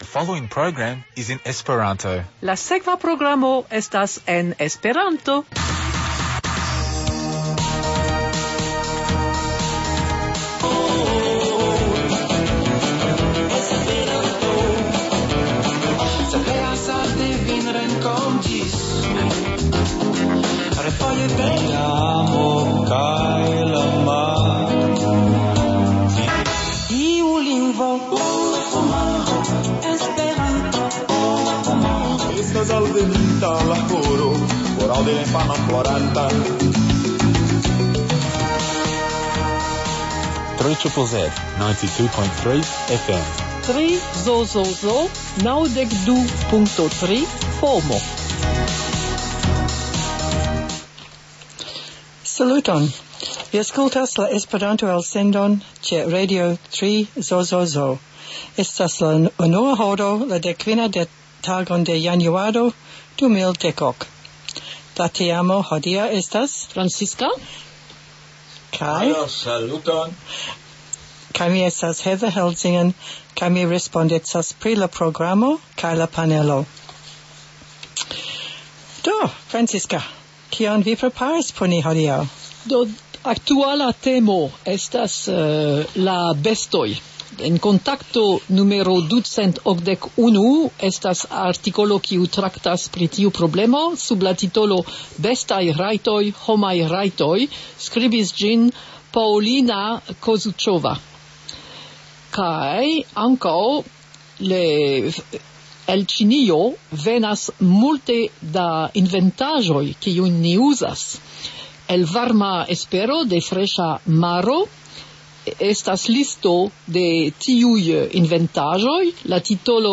Legado